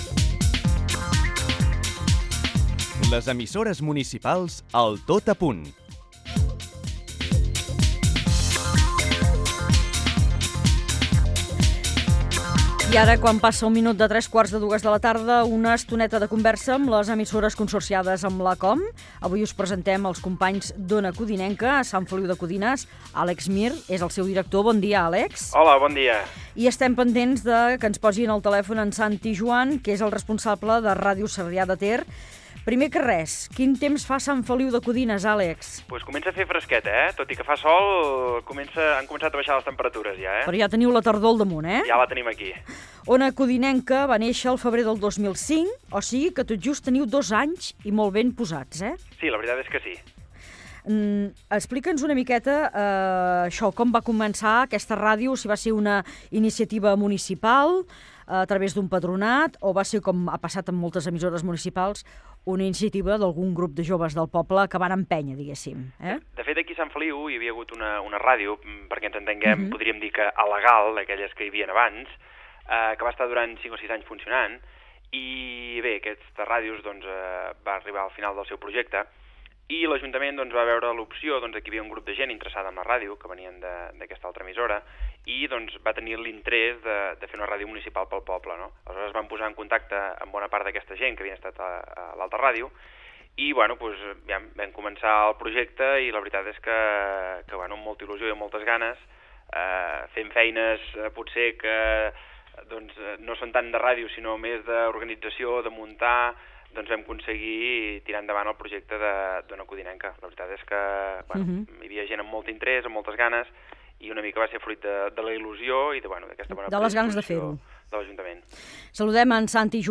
Emissora
Entreteniment
FM
Fragment extret de l'arxiu sonor de COM Ràdio.